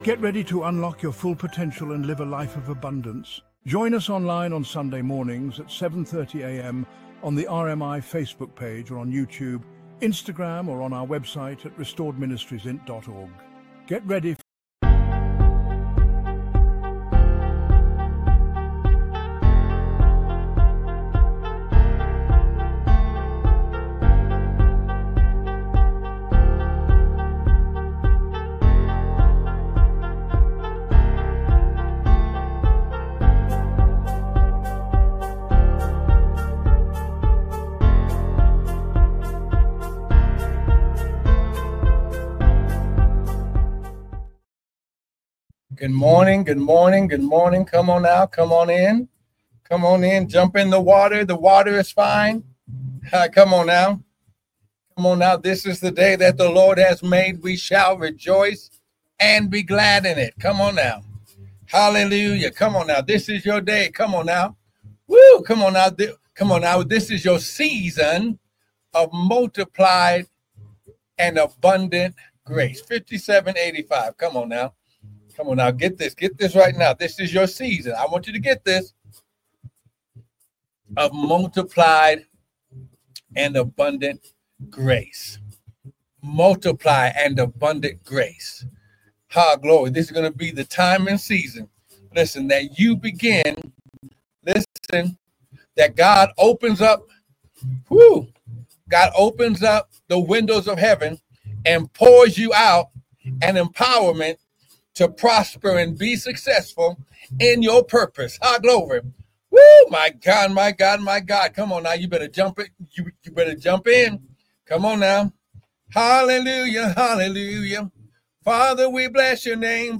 1 live-recording 4/26/2024 7:03:15 AM